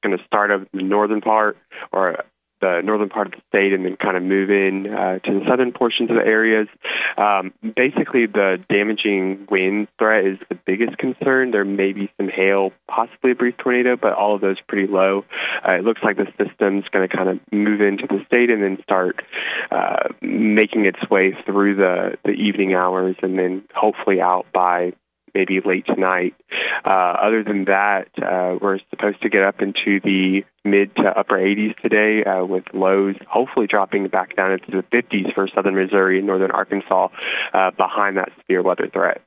Meteorologist